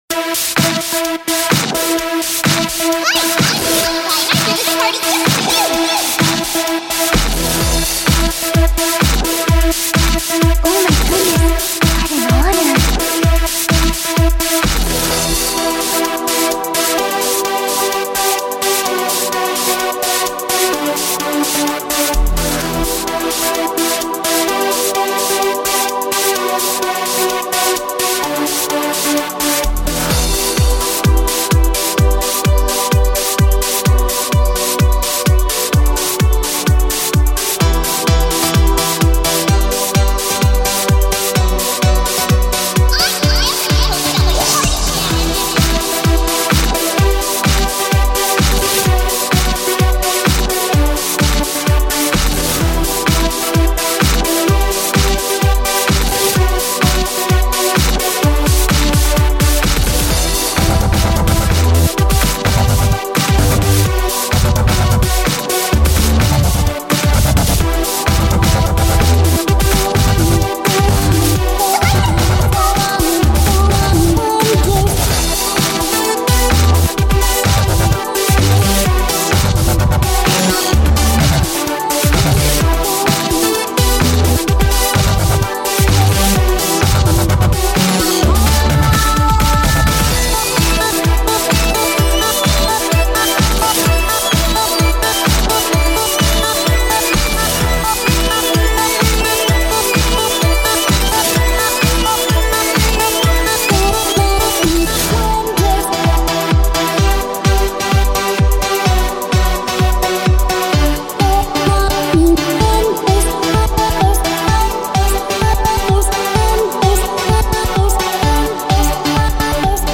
Hoooray for Electro!